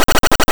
Poisoned.wav